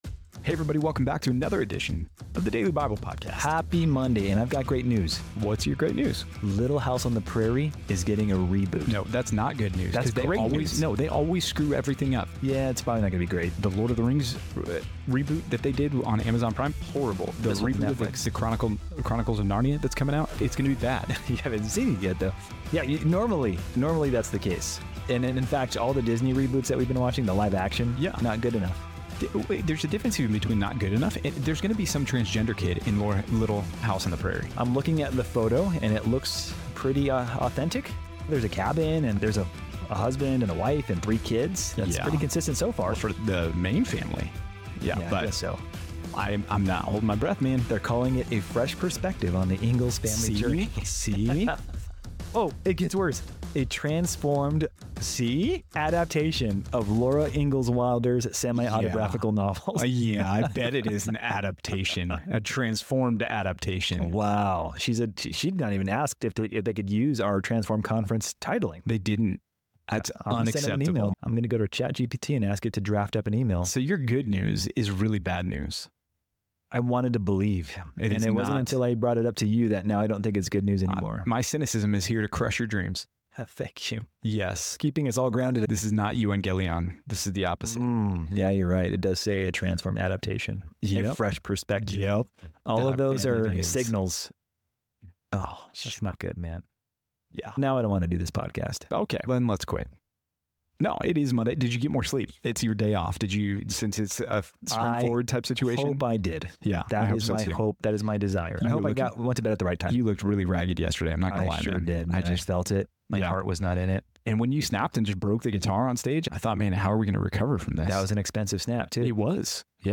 In this episode of the Daily Bible Podcast, the hosts start with a conversation about TV reboots and transition into a discussion of Numbers 35-36. They explore the biblical laws regarding Levitical cities, manslaughter versus murder, and the importance of sober-minded living. The episode concludes with reflections on contentment and obedience, highlighting the daughters of Zelophehad and the permanence of land allotments among the tribes of Israel.